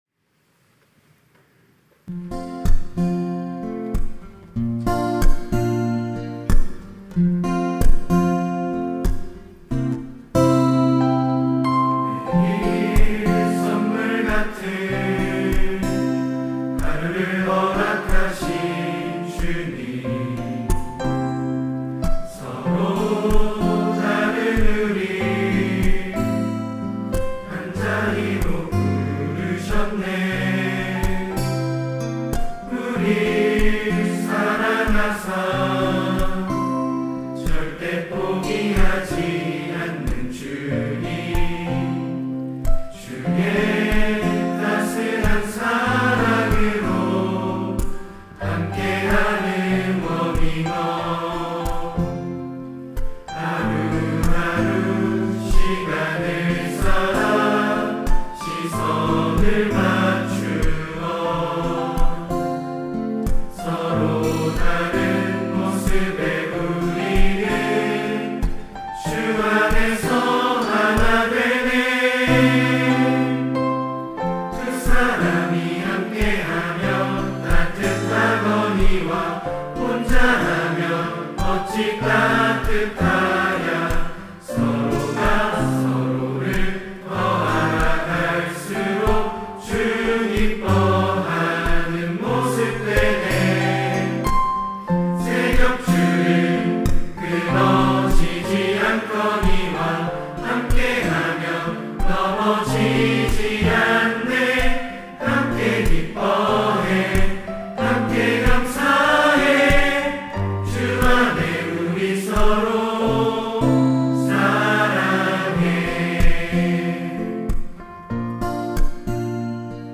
특송과 특주 - 함께하는 워밍업
청년부 양육 1팀